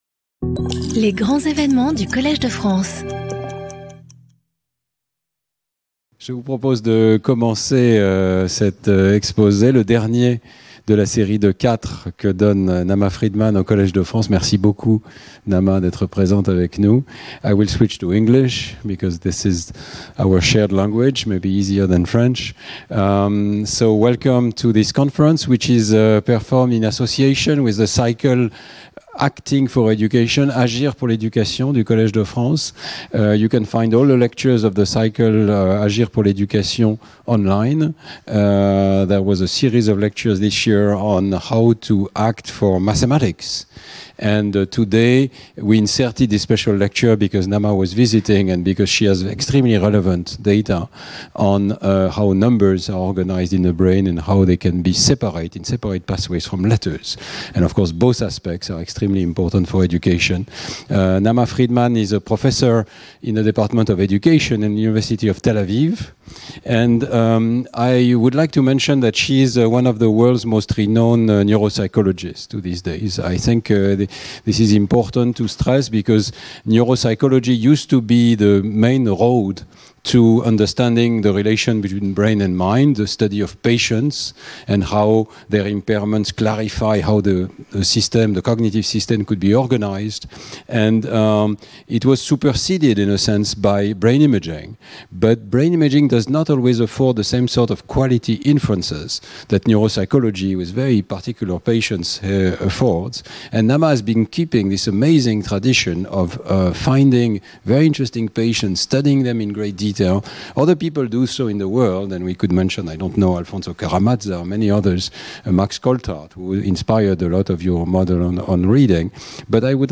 Skip youtube video player Listen to audio Download audio Audio recording Access the live stream on the YouTube channel of the Collège de France Foundation Conference in English.